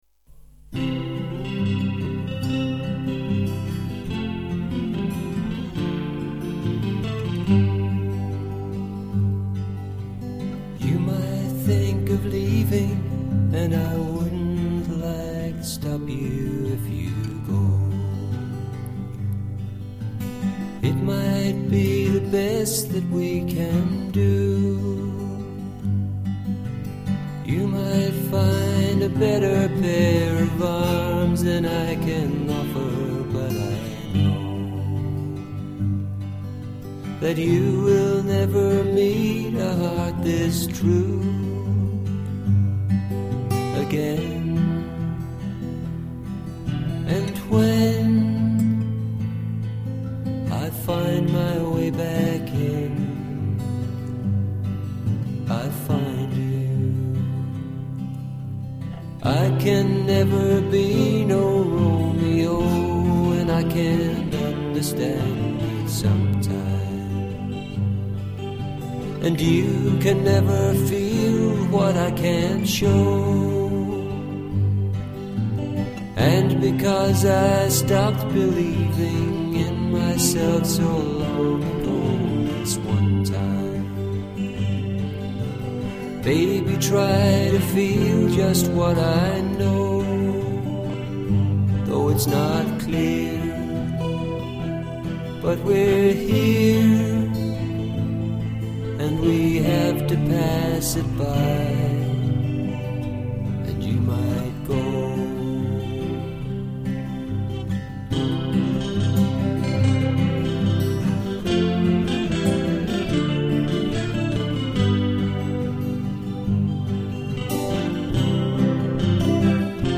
It was mastered at too low a volume.
There are a few other things you'd be better off doing before you lift the vol. View attachment val.mp3 You'll note that the norm didn't do much. It lifts as much as it can buut only as much as the loudest part will allow & the track has a few loud moments that prevent this doing much.